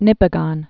(nĭpĭ-gŏn), Lake